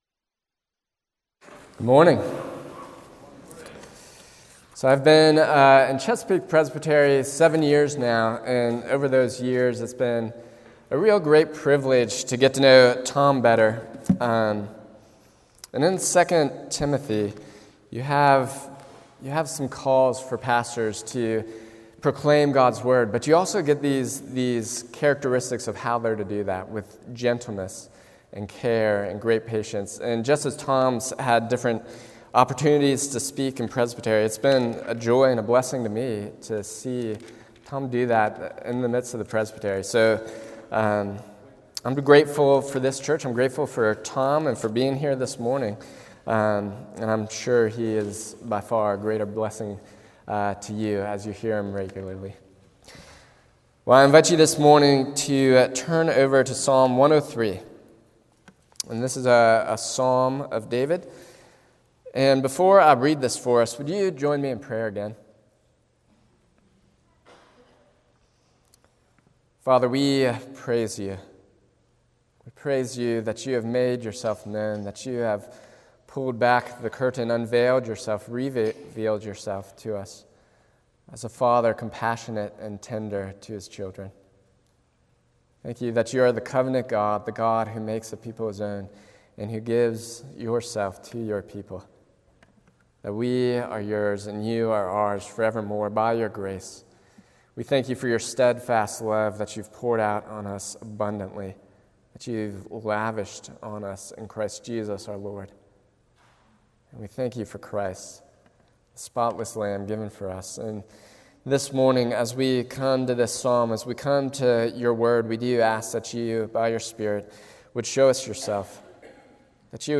A message from the series "Psalms (Summer 2023)."